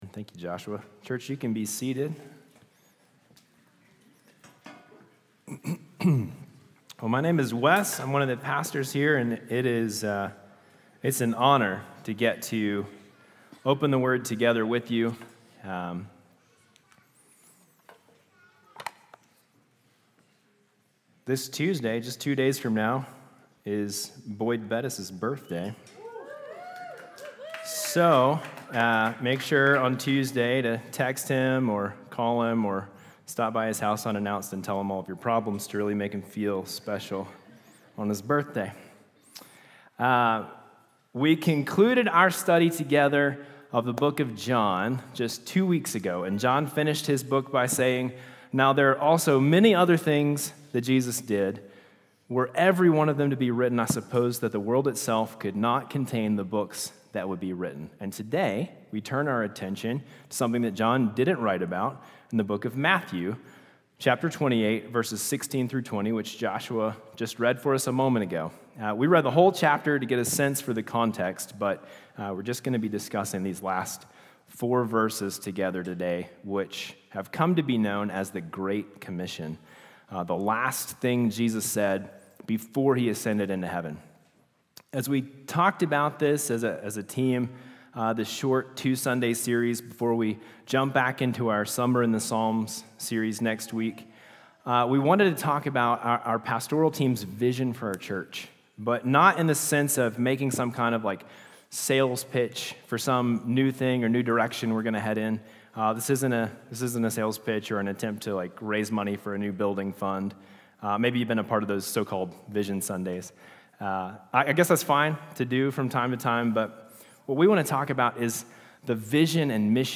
Vision & Values Meet Our Team Statement of Faith Sermons Contact Us Give Saturate the Valley | Week 2 | Matthew 28:1-20 May 26, 2024